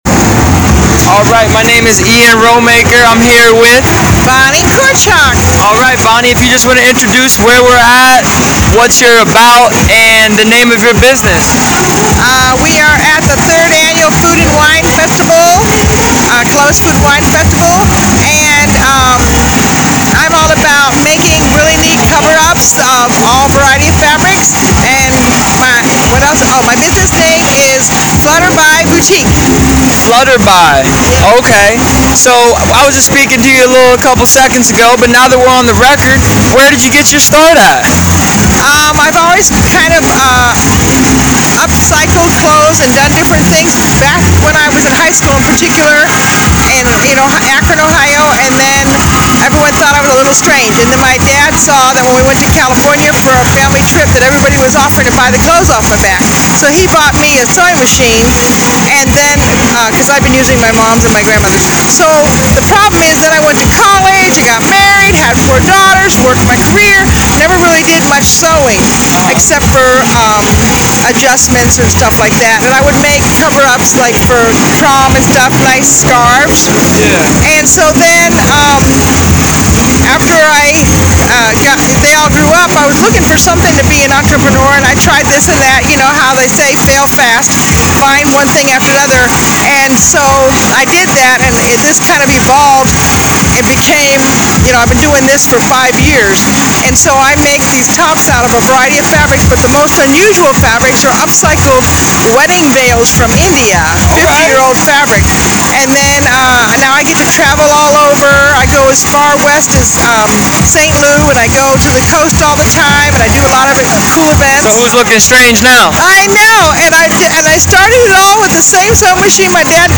Festival Interview
Here is the conversation that she enthralled us with at the 3rd annual Food & Wine Festival in Columbus, Ohio.